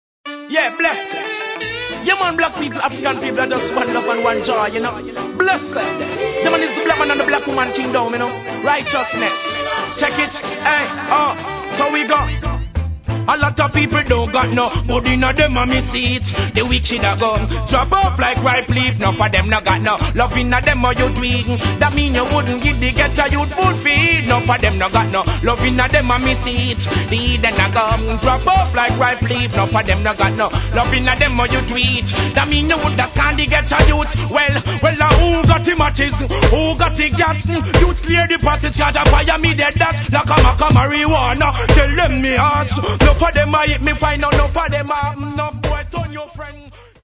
Please post only reggae discussions here
in the intro you can hear a sample